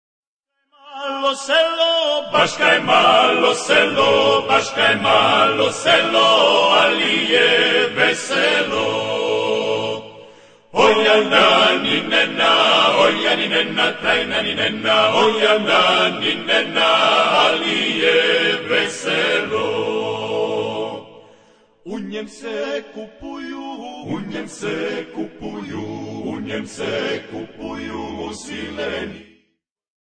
Dalmatian traditional a capella song